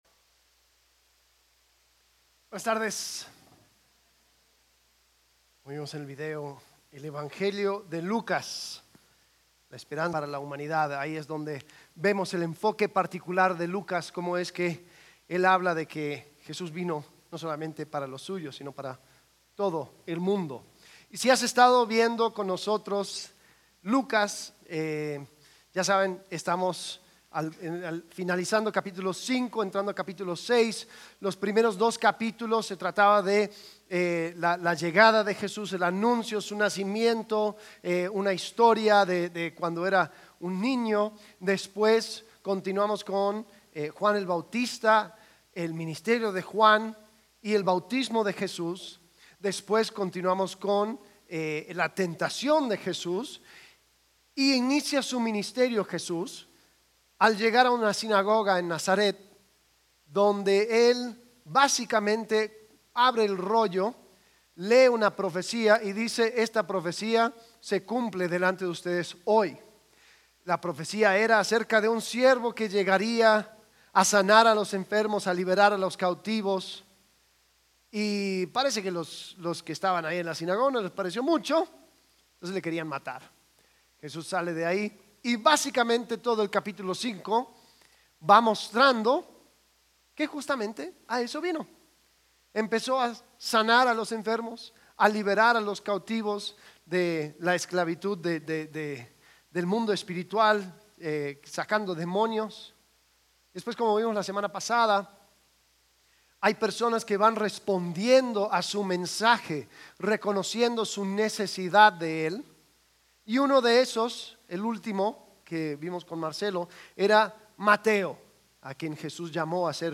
Lucas Servicio: Domingo « Lucas 5:1-32.